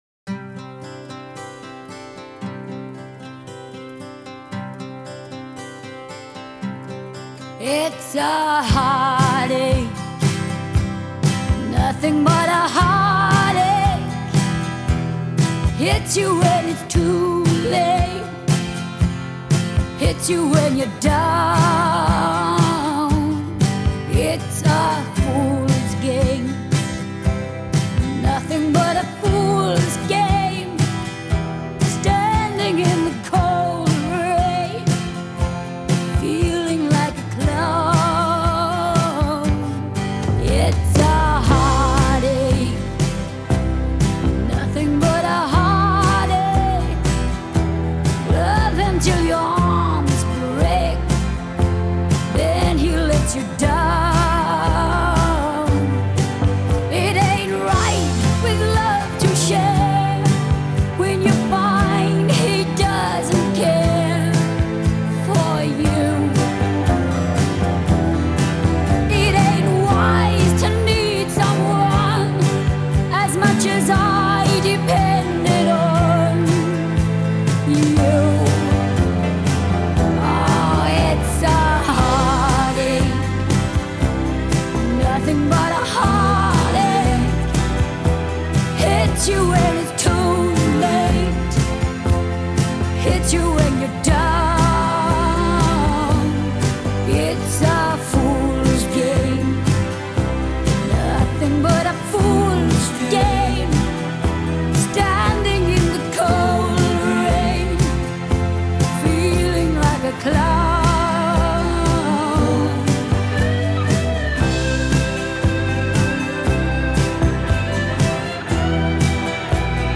Genre: Pop Rock, Synth-pop, Disco